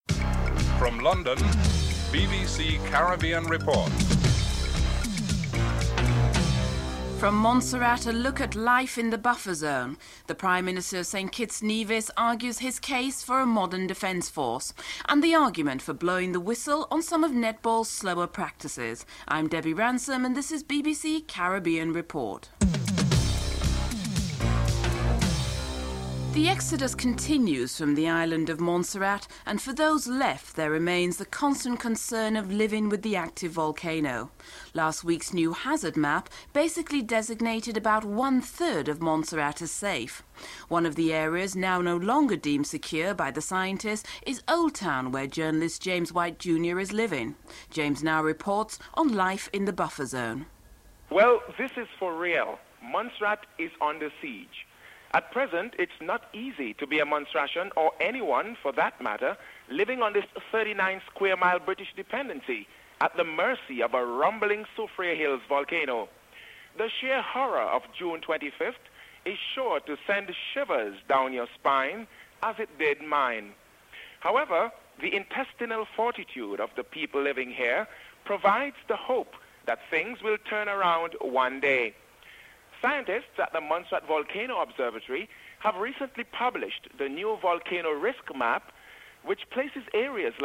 1. Headlines (00:00-00:25)
4. St. Lucia's Prime Minister Kenny Anthony outlines his country's help for Montserrat.